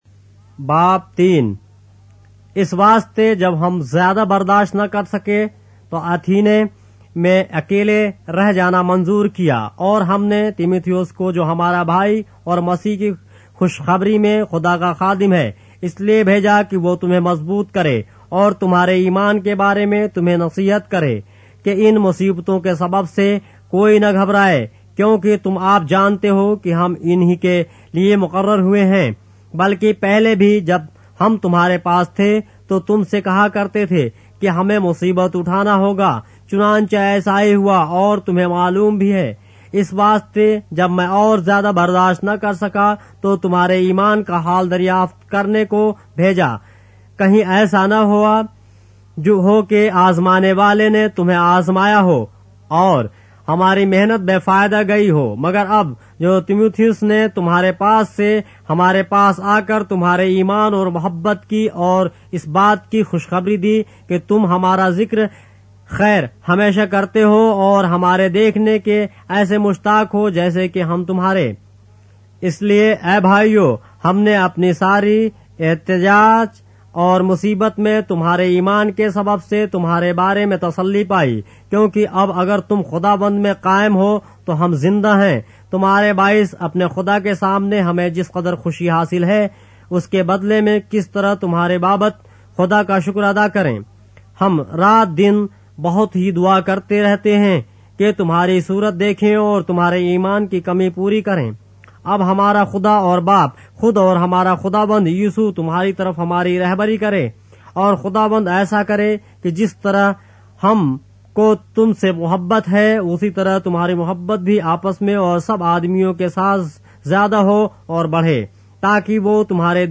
اردو بائبل کے باب - آڈیو روایت کے ساتھ - 1 Thessalonians, chapter 3 of the Holy Bible in Urdu